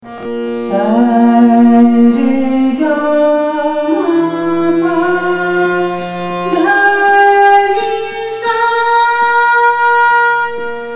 ArohaS R G M P D N S’